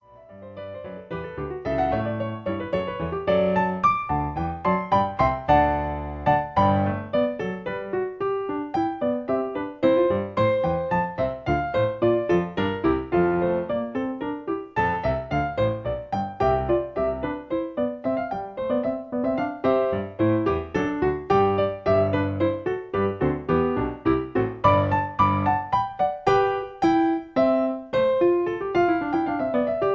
Original Key (C). Piano Accompaniment